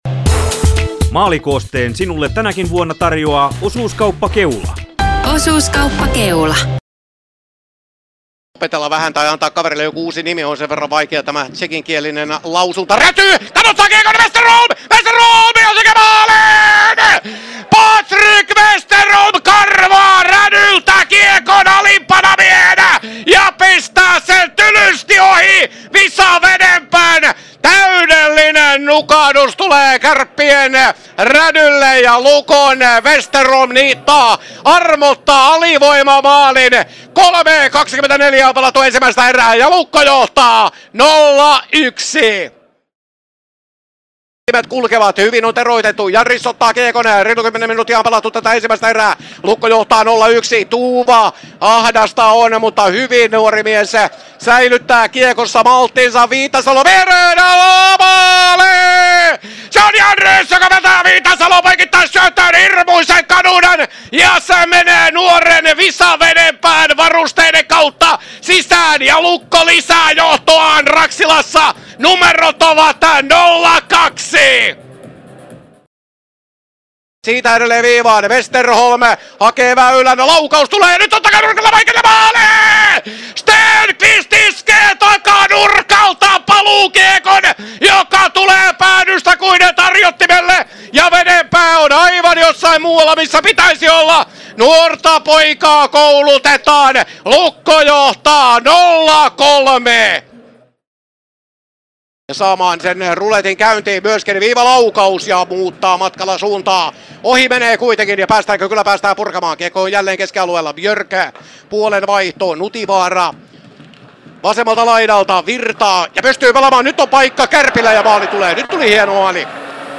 Maalikooste Kärpät-Lukko 24.9.2025